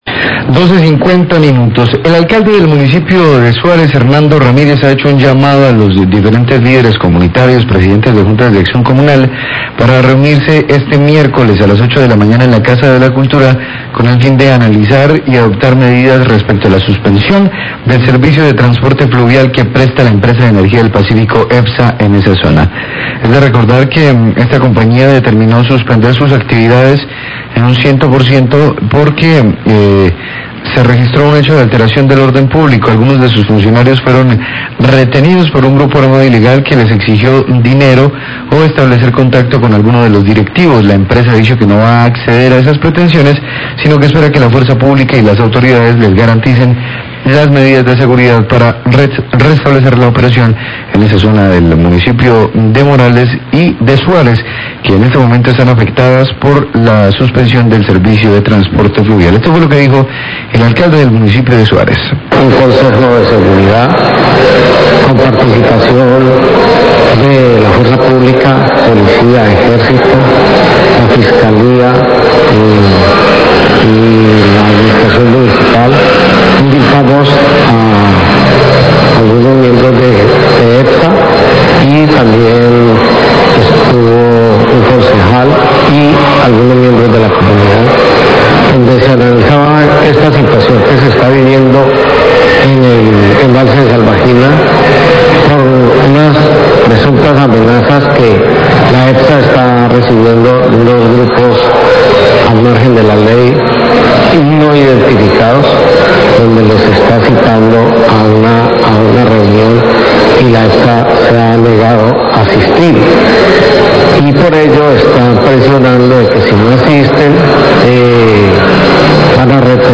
Radio
El Alcalde de Suárez has hecho un llamado para reunirse este miércoles con el fin de analizar y adoptar medidas respecto a la suspensión del servicio de transporte fluvial que presta Epsa, el cual fue suspendido por alteraciones de orden público. Declaraciones del Alcalde de Suárez, Hernando Ramirez.